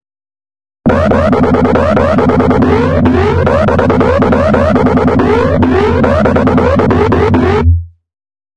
描述：140 bpm 2 bars (8/4) dubstep bass。由自制的贝斯样本制成（标语有更多信息）。
Tag: 低音 贝斯 回响贝斯 循环 摇摆